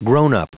Prononciation du mot grown-up en anglais (fichier audio)
Prononciation du mot : grown-up